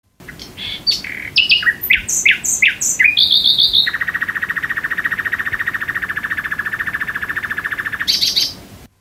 Голоса природы (400)
Правильный ответ: Соловей